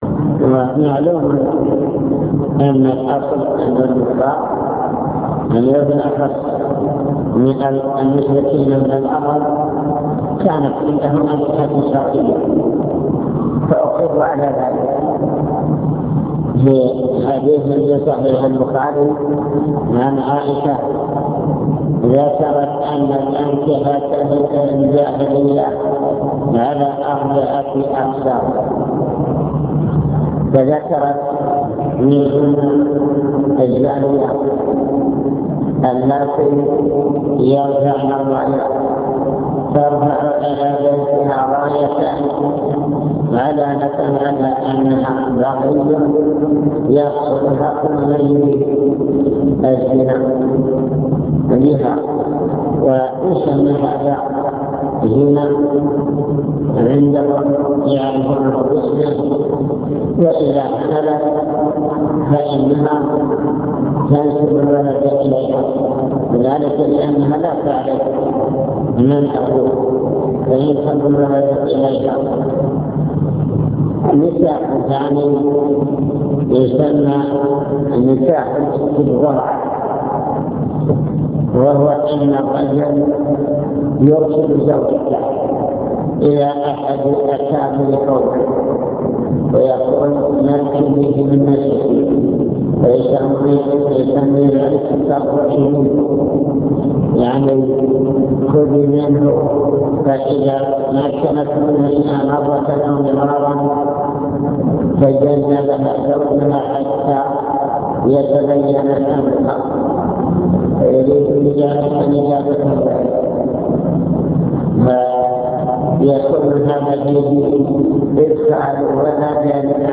المكتبة الصوتية  تسجيلات - محاضرات ودروس  محاضرات في الزواج